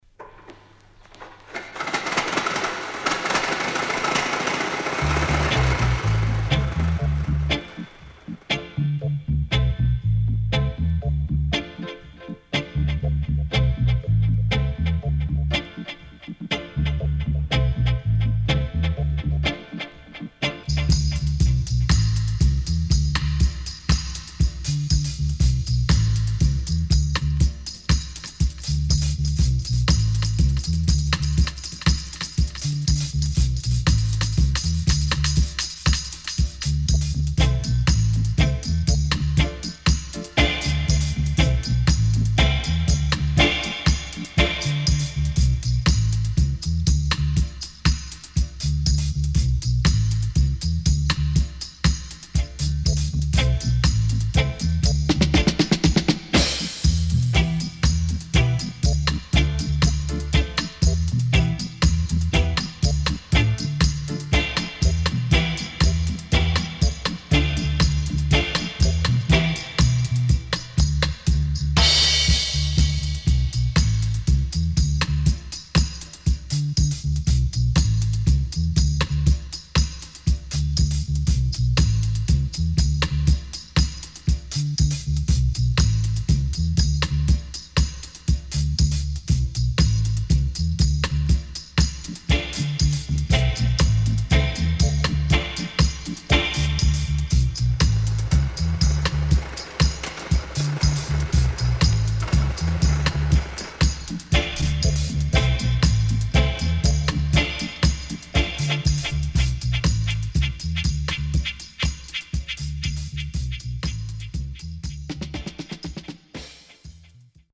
HOME > Back Order [VINTAGE DISCO45]  >  INST 70's
SIDE B:少しノイズ入りますが良好です。